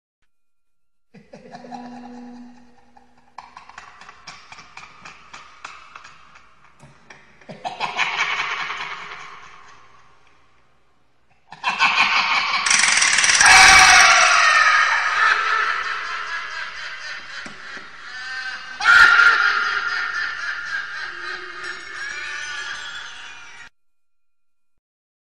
Kategori Ses Efektleri